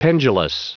Prononciation du mot pendulous en anglais (fichier audio)
Prononciation du mot : pendulous
pendulous.wav